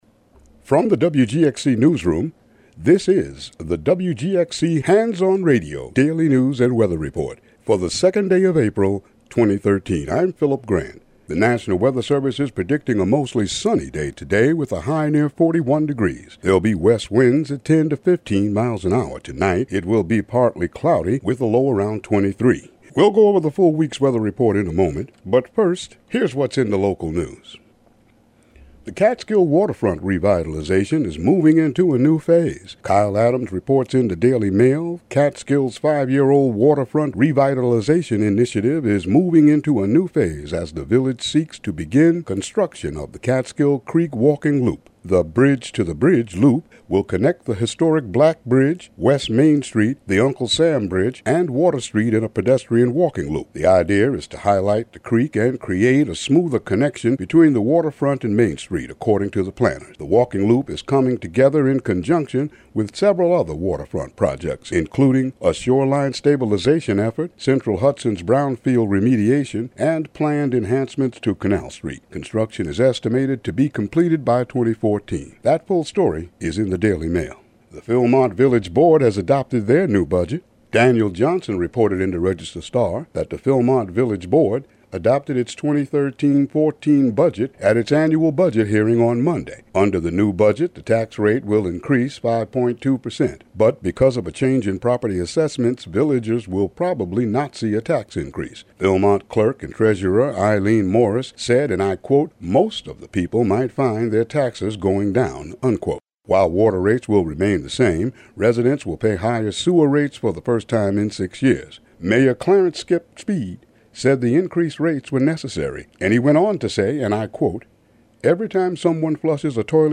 Local news and weather for April 2, 2013.